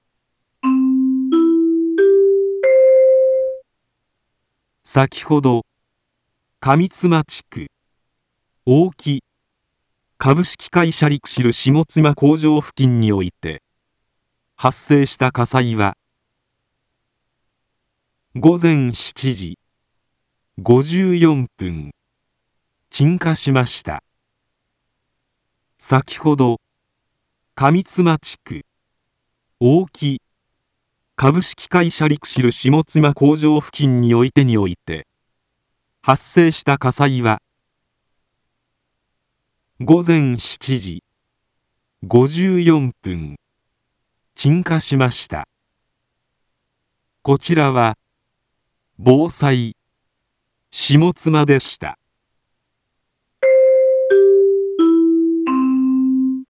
Back Home 一般放送情報 音声放送 再生 一般放送情報 登録日時：2023-08-20 08:10:59 タイトル：鎮火報 インフォメーション：先程、上妻地区、大木かぶしきかいしゃリクシルしもつまこうじょうふきんにおいて発生した火災は、 午前７時、５４分、鎮火しました。